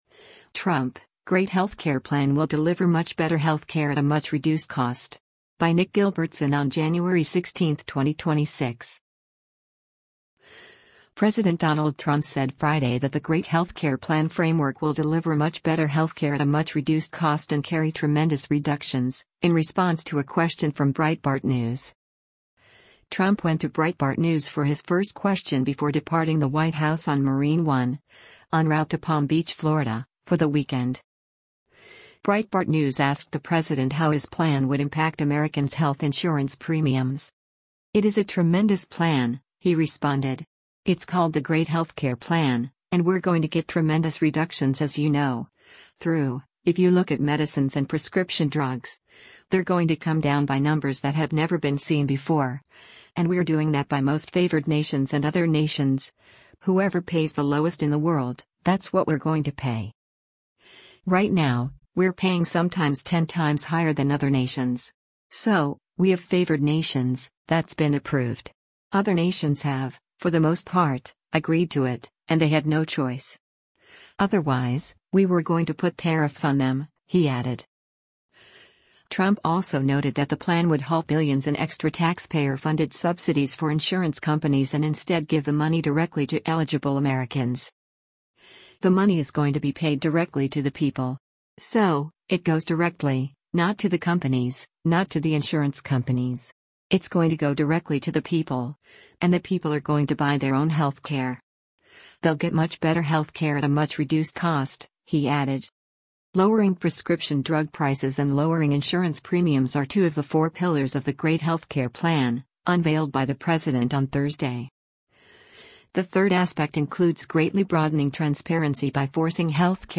Trump went to Breitbart News for his first question before departing the White House on Marine One, en route to Palm Beach, Florida, for the weekend.